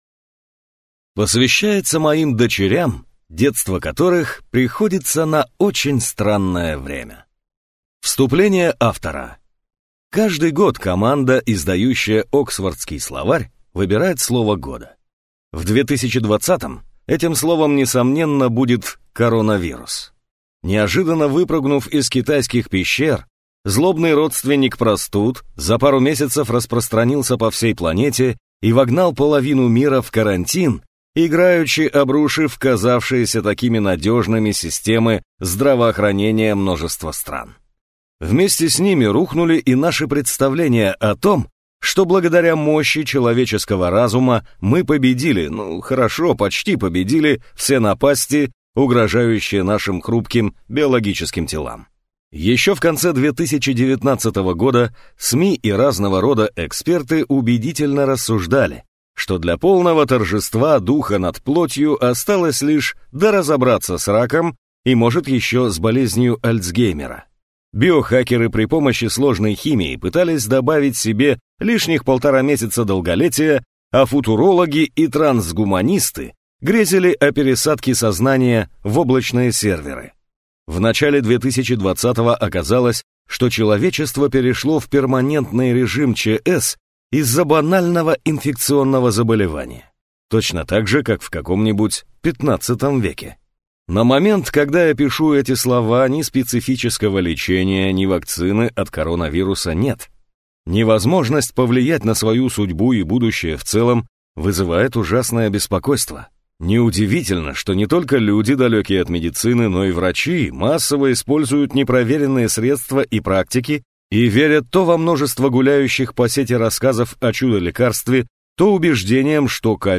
Аудиокнига Вирус, который сломал планету. Почему SARS-CoV-2 такой особенный и что нам с ним делать | Библиотека аудиокниг